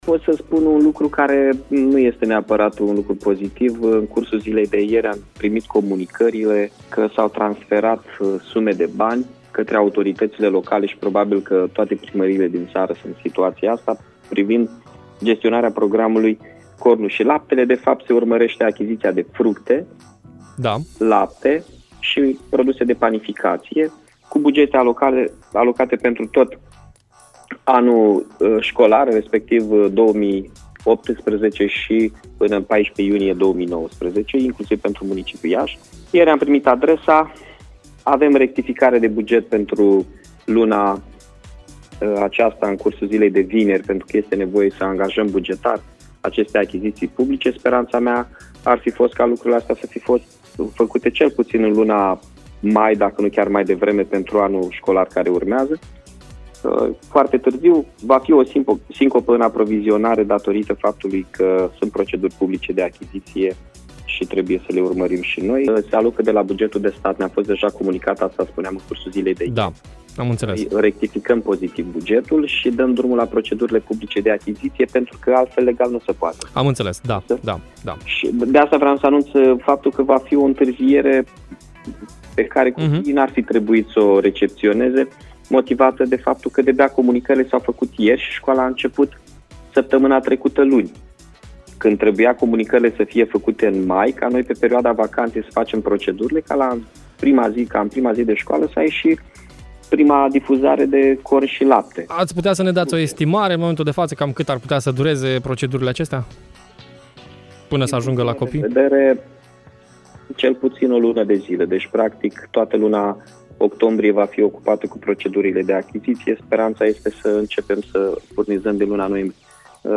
Declarația a fost făcută de primarul municipiului Iași la Radio HIT.